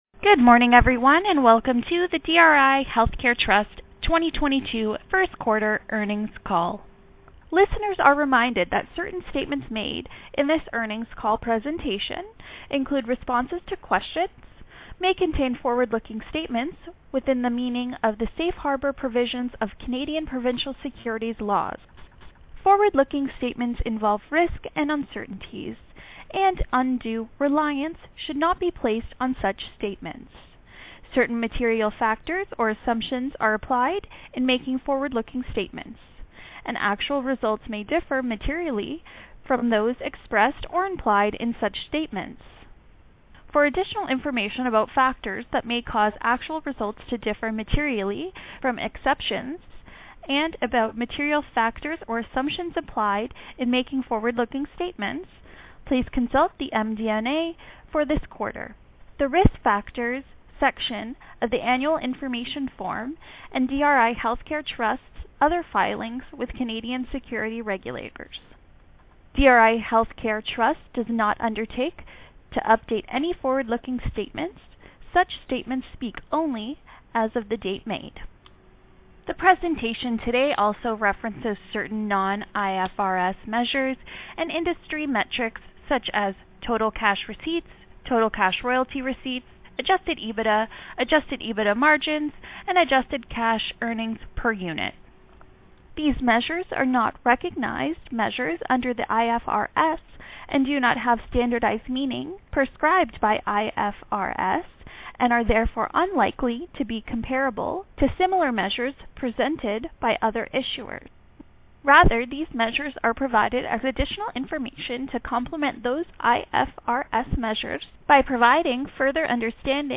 2022 First Quarter Earnings Call - DRI Healthcare